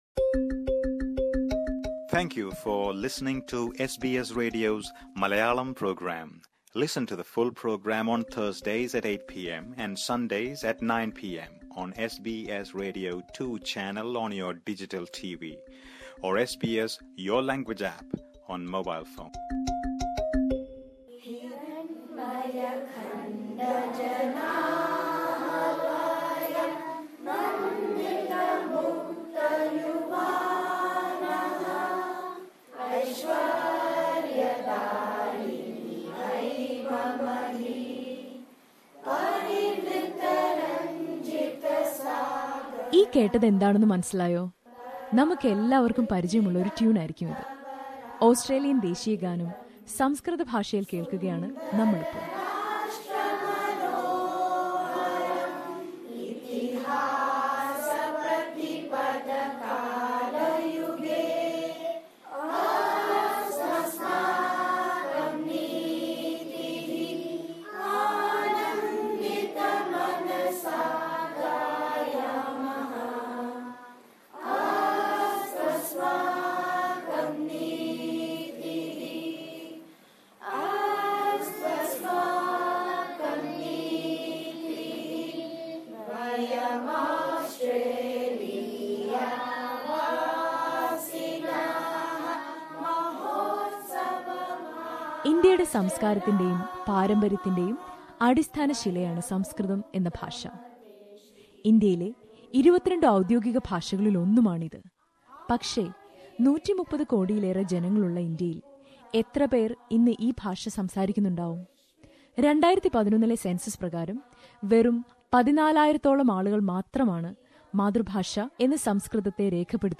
Listen to a report on the Sydney Sanskrit School, which is one of the largest Indian language schools in Australia...